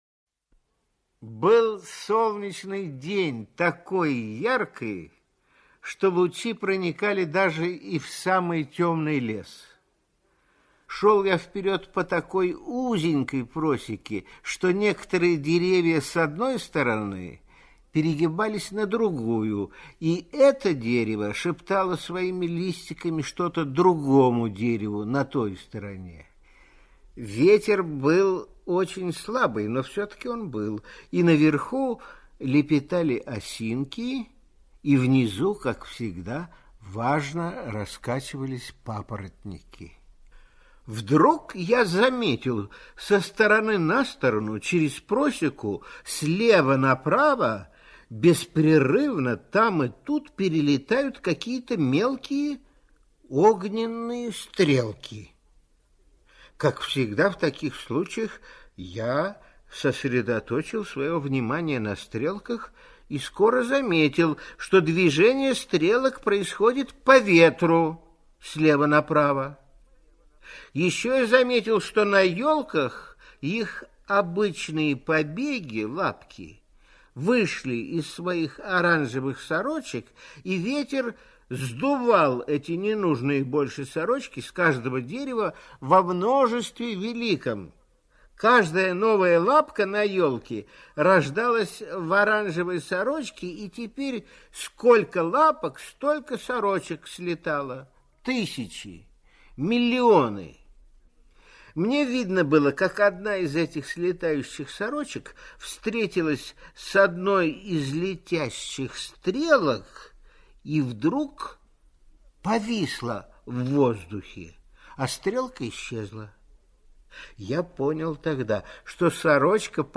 Паутинка – Пришвин М.М. (аудиоверсия)
Текст читает Николай Литвинов.